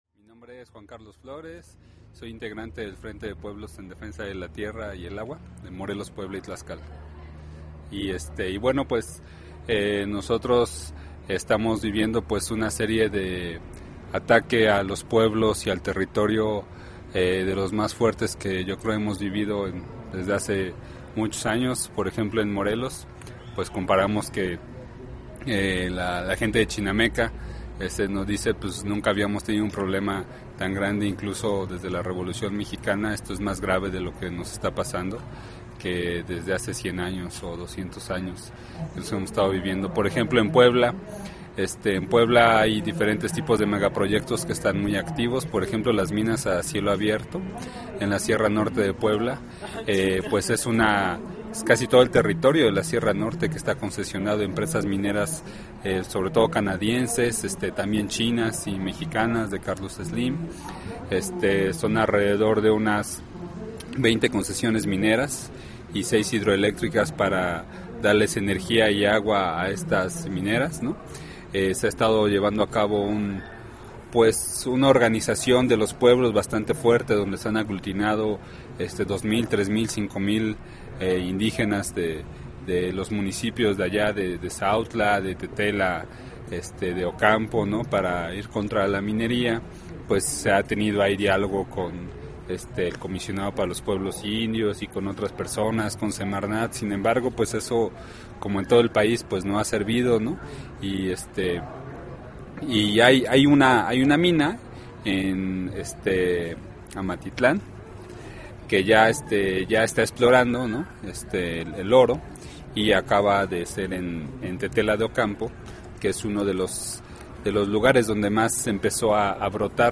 denuncia en entrevista con Regen